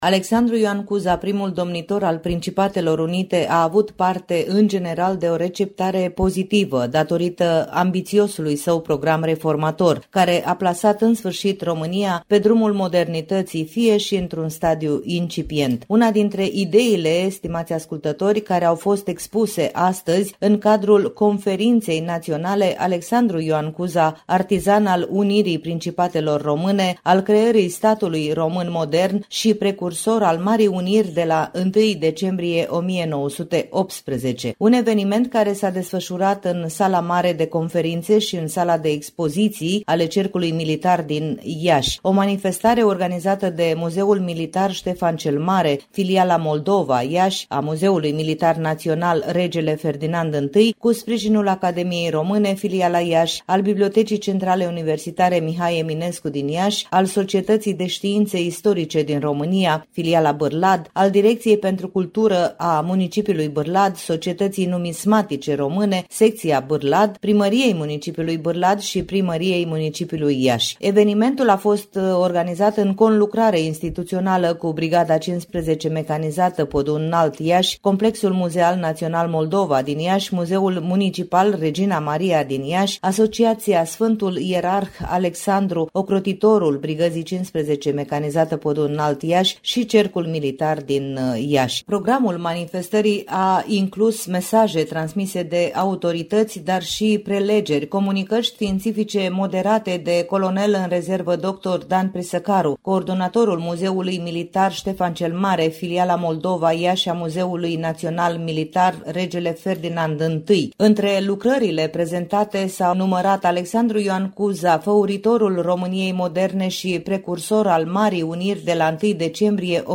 Activitățile au avut loc, marți, 21 ianuarie 2025, începând cu ora 10.00, în Sala Mare de Conferințe și în Sala de Expoziții a Cercului Militar Iași, B-dul Carol I nr. 7, Copou.